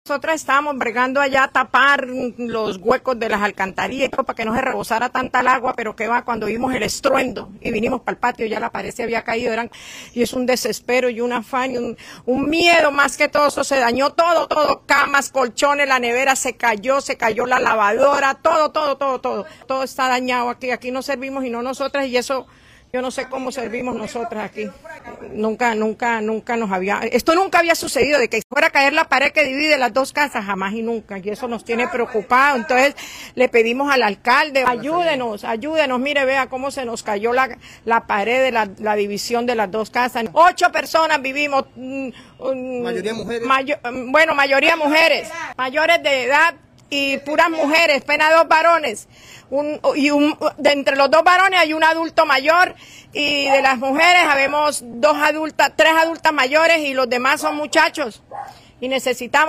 comunidad afectada